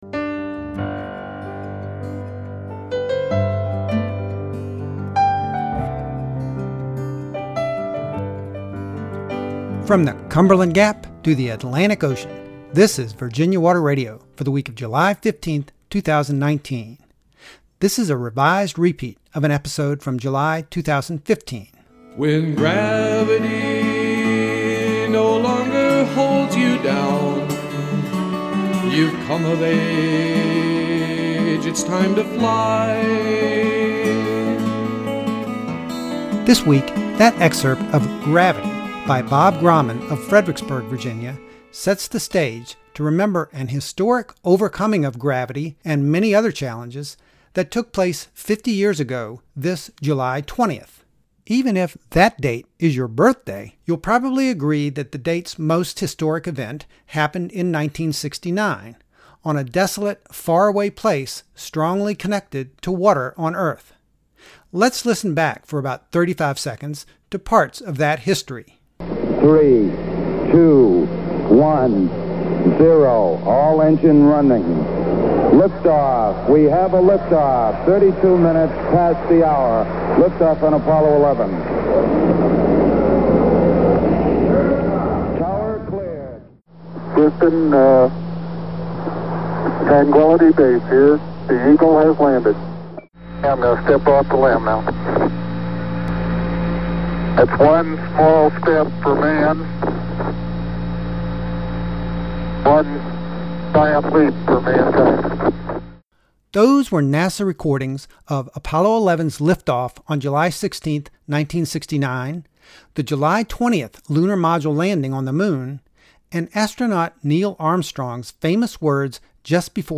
SHIP’S BELL